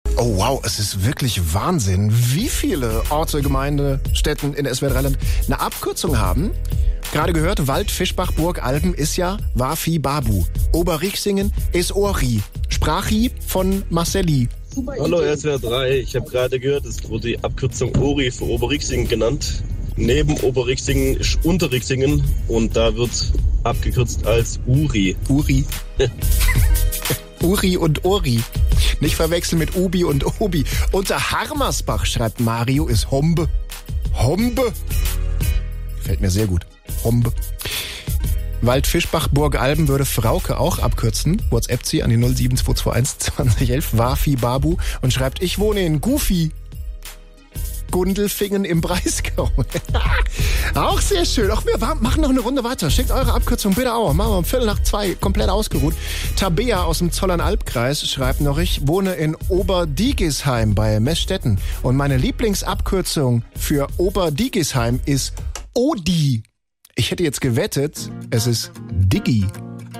Eure Abkürzungen von Städtenamen im Radio